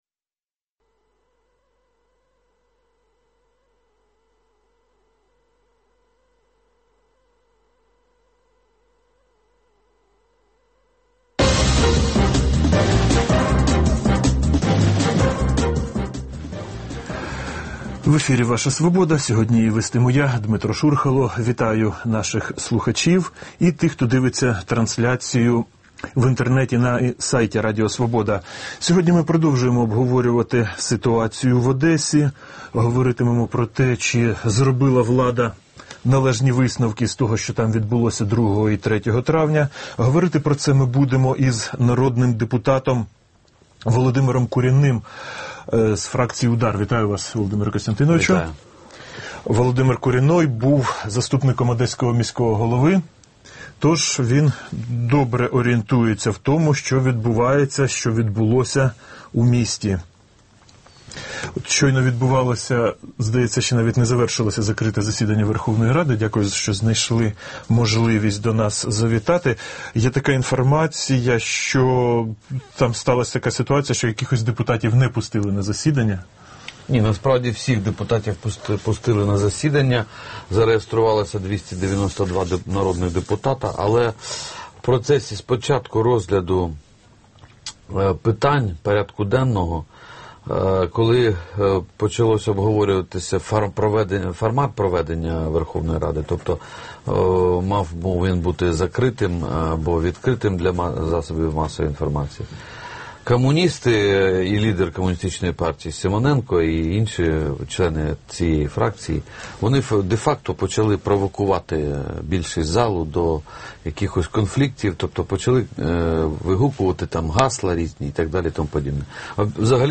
Гості: Сергій Гриневецький, перший заступник голови Комітету Верховної Ради з питань національної безпеки та оборони, член фракції Партії регіонів, голова Одеської ОДА (1998–2005); Володимир Куренной, народний депутат від партії УДАР